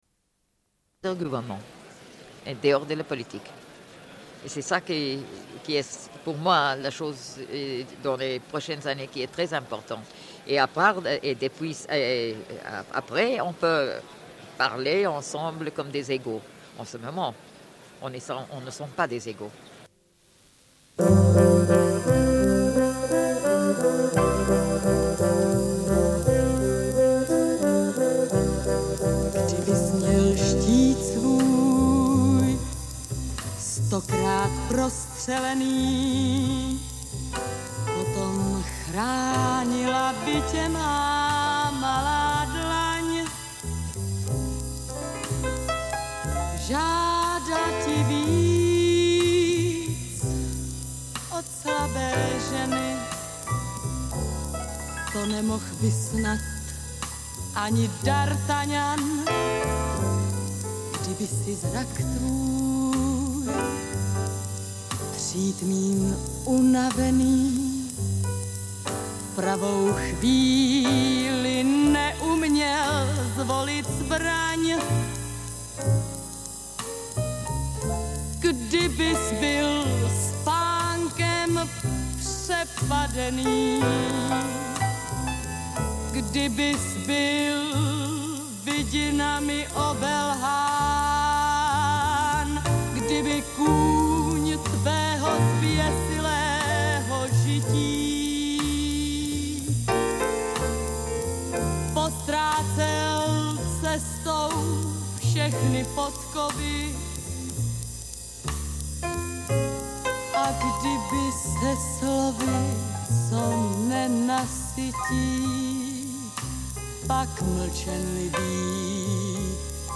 Une cassette audio, face B00:29:01